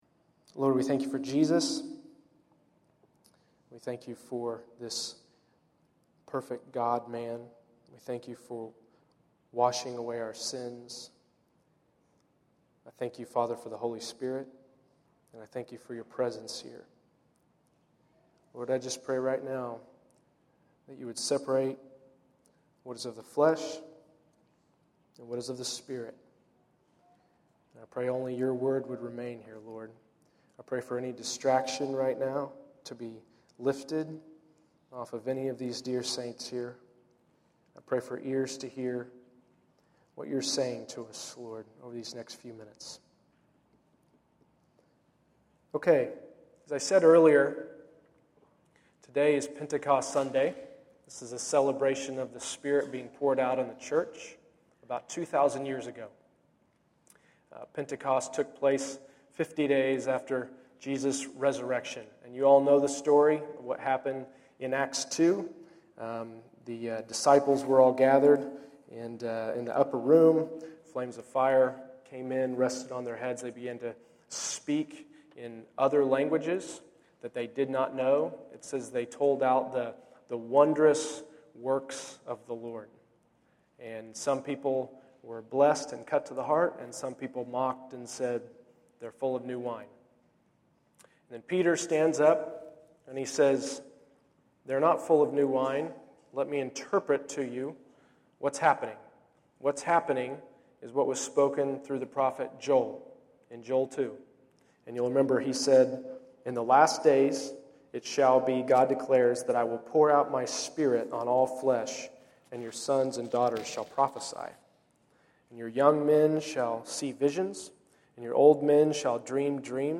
Pentecost Sunday: The Spirit is Poured Out and The Church Prophesies May 19, 2013 Category: Sermons